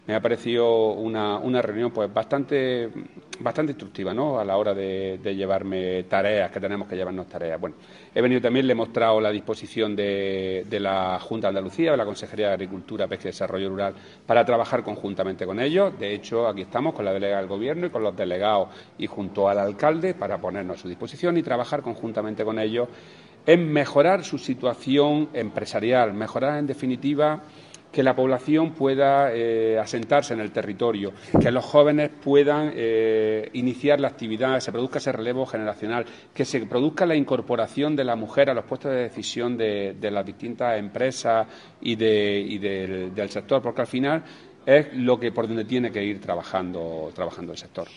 Declaraciones de Rodrigo Sánchez sobre su reunión con el sector cárnico en Serón (Almería)